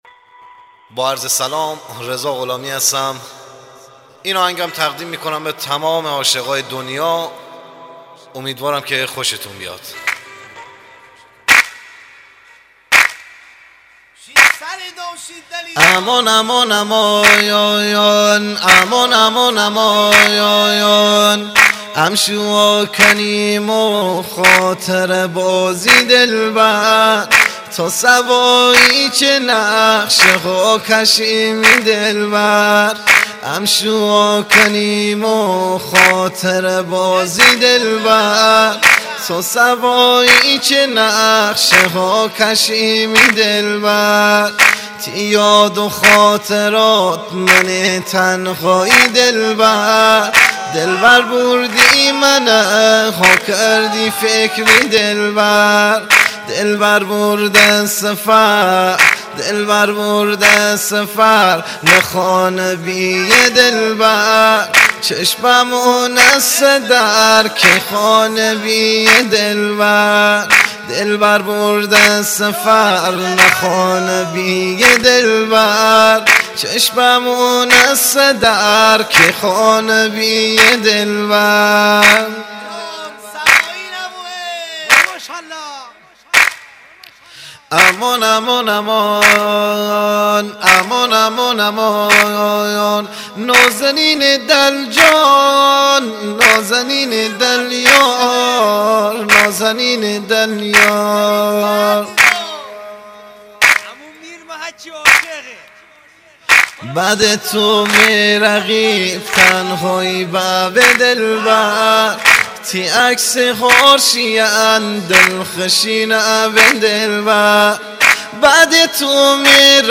آهنگ مازندرانی
آهنگ شاد